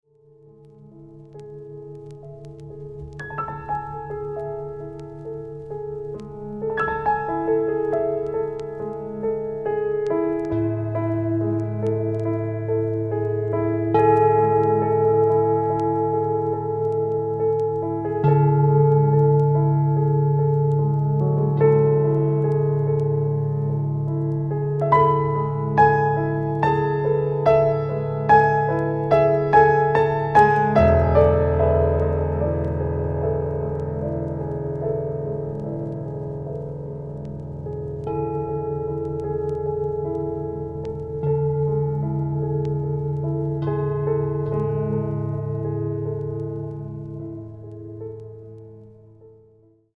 即興